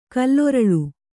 ♪ kalloraḷu